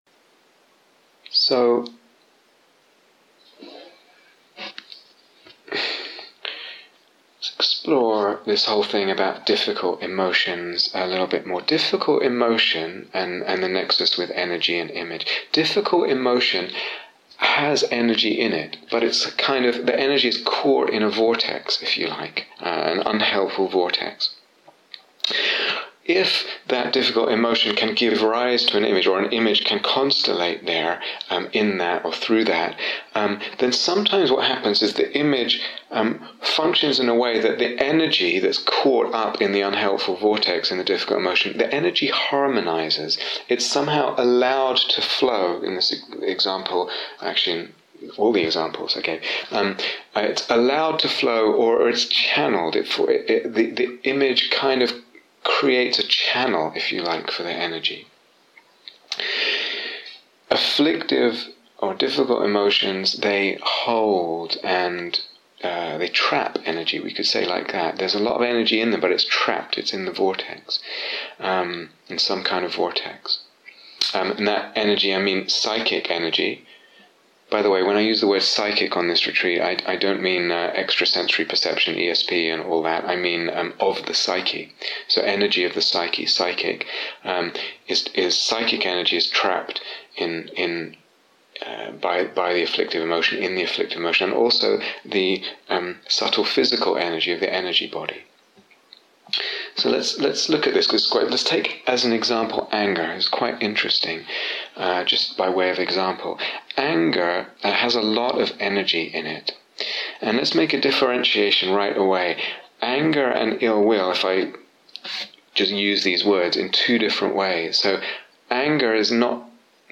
Energy, Image, Emotion (Part 2) The set of talks and meditations from this course outlines the foundations and some of the possibilities for opening up a practice of the imaginal.